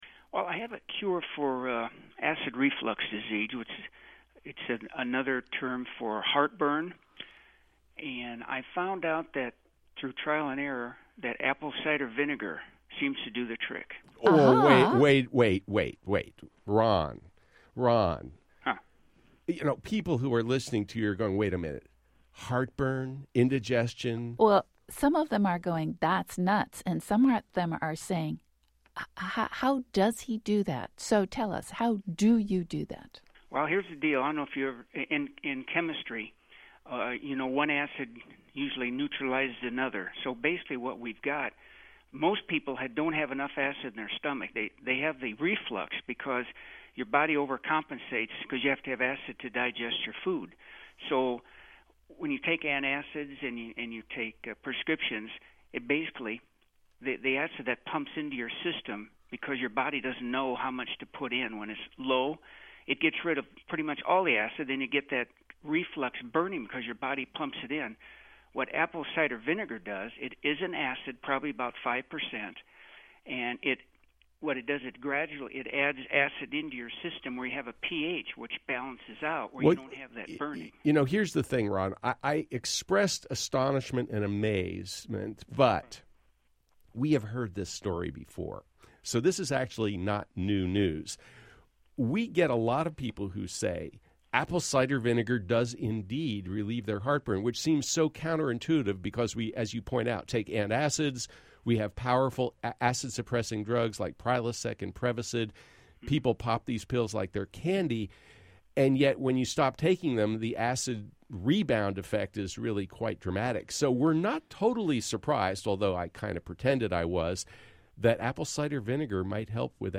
In this sound clip, a radio show listener reports his experience using apple cider vinegar to alleviate symptoms of heartburn.